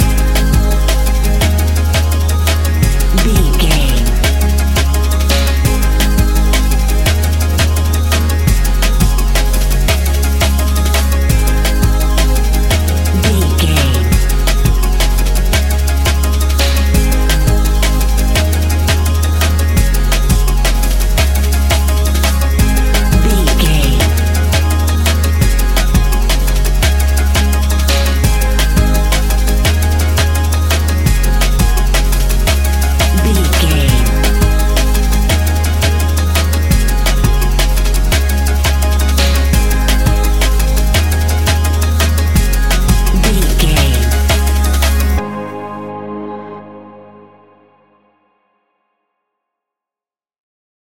Ionian/Major
C♭
electronic
techno
trance
synths
synthwave